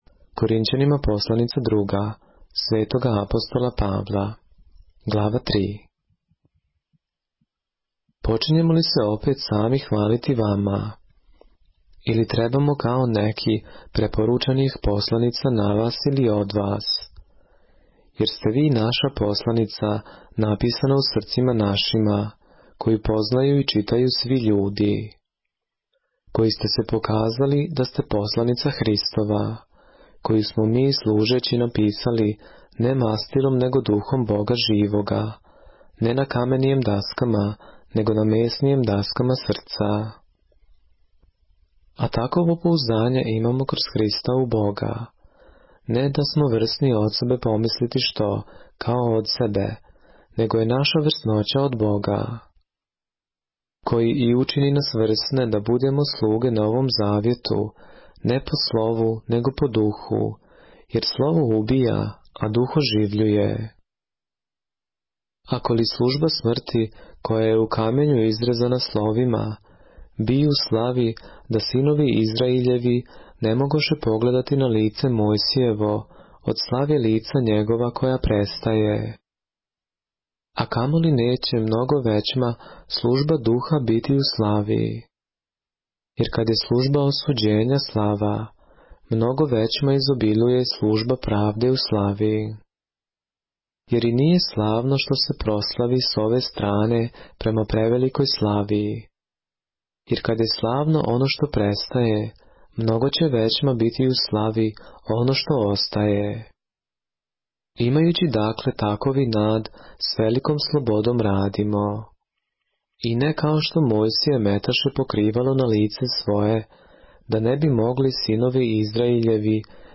поглавље српске Библије - са аудио нарације - 2 Corinthians, chapter 3 of the Holy Bible in the Serbian language